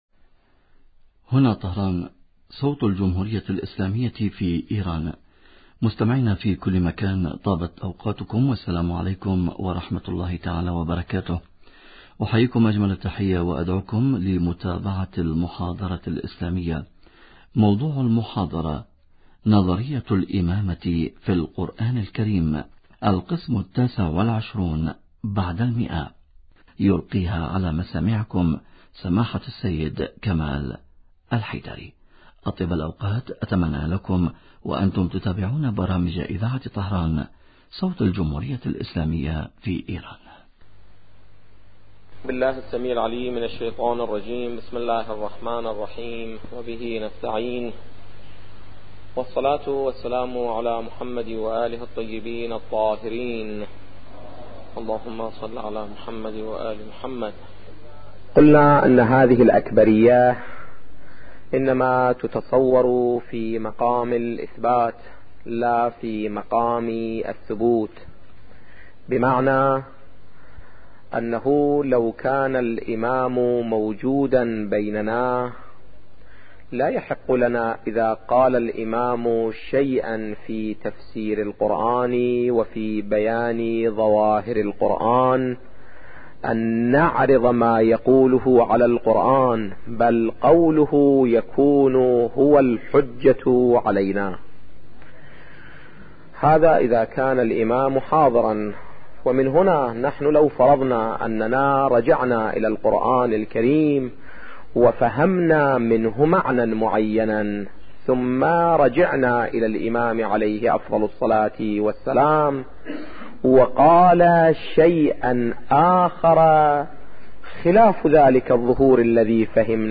نظرية الامامة في القران الكريم - الدرس التاسع والعشرون بعد المئة